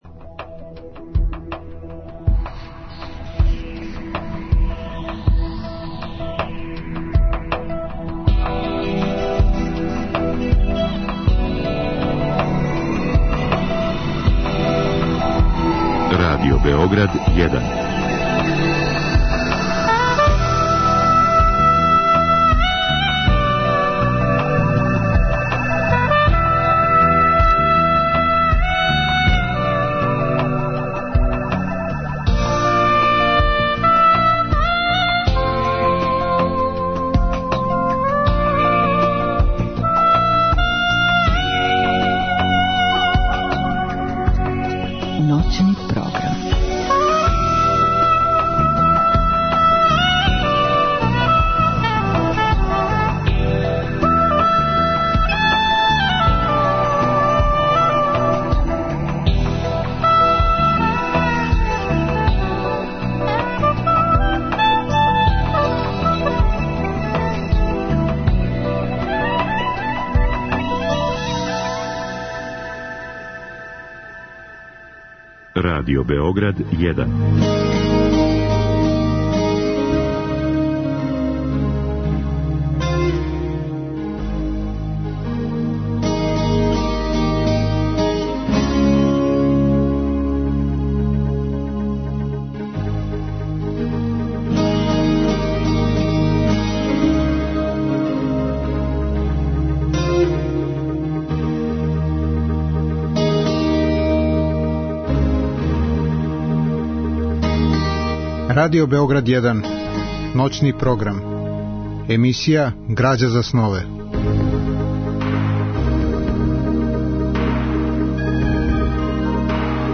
Разговор и добра музика требало би да кроз ову емисију и сами постану грађа за снове.
У другом делу емисије, од два до четири часa ујутро, слушаћемо делове радио-драме Јасмине Њаради Пабло Пикасо , коју је режирао Бода Марковић.